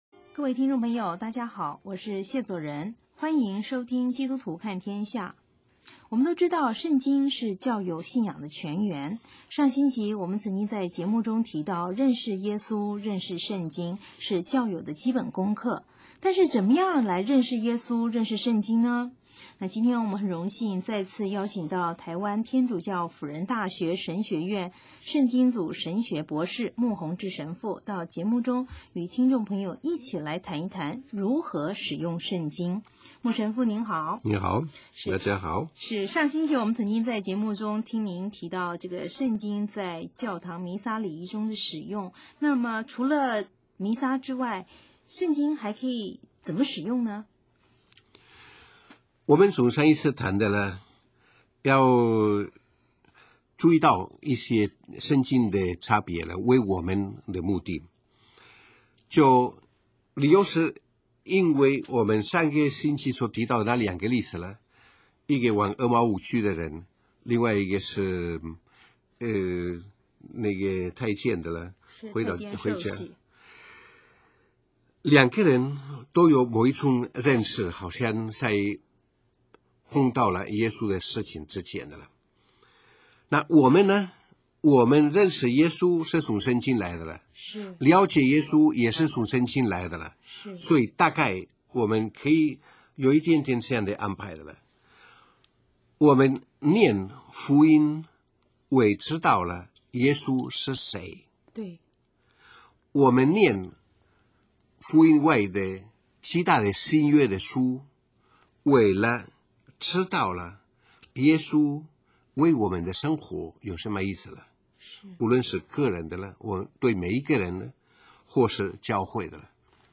来 宾